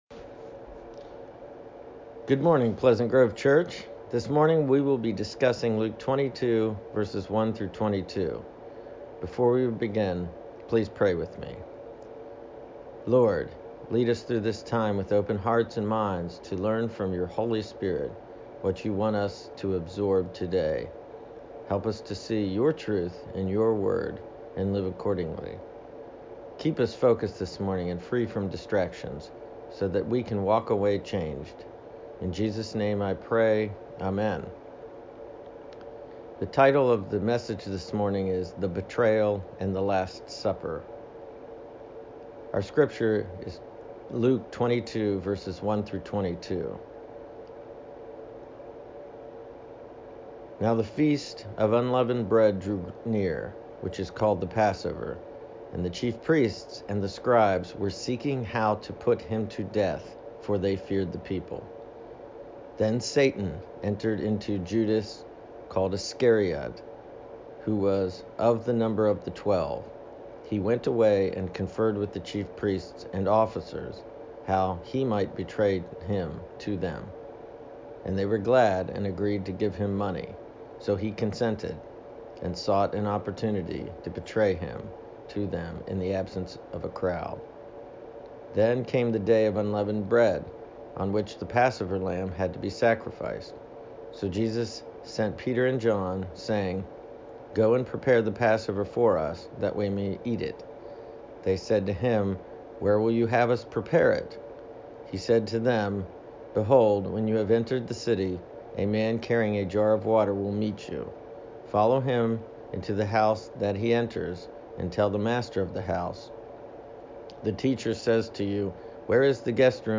Passage: Luke 22:1-22 Service Type: Worship Service